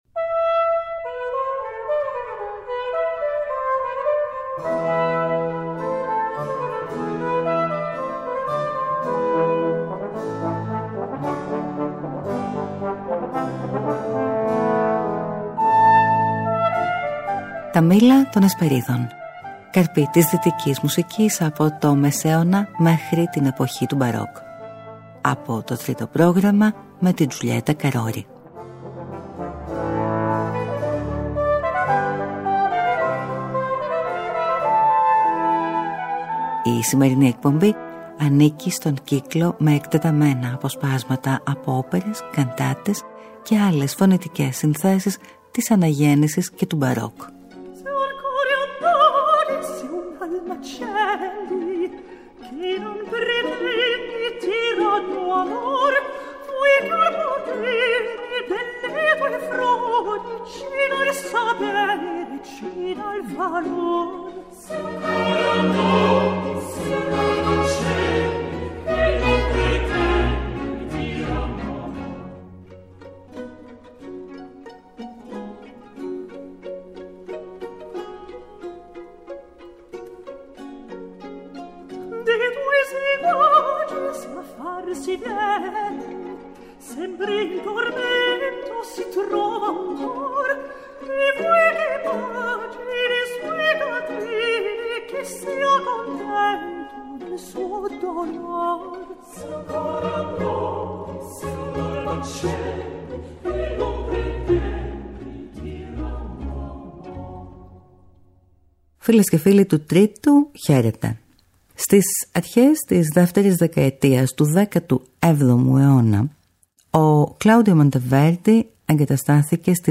Μια μινιατούρα όπερας, που προαναγγέλλει τη δραματική καντάτα.